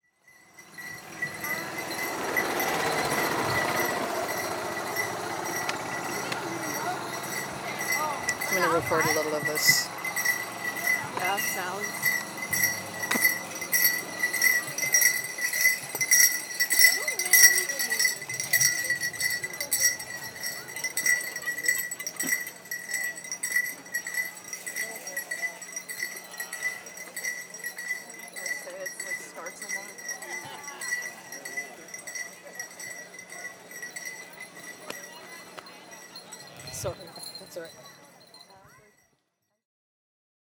Menominee PowWow 4 Aug 2023 PW Jingle walks by.wav